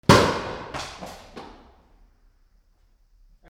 / H｜バトル・武器・破壊 / H-05 ｜銃火器
発砲銃 02